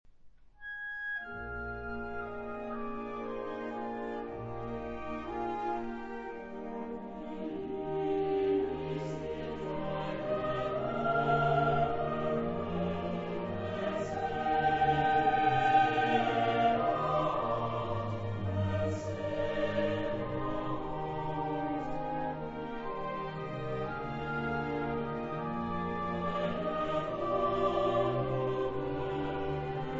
baritono
soprane
• registrazione sonora di musica